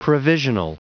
Prononciation du mot provisional en anglais (fichier audio)
Prononciation du mot : provisional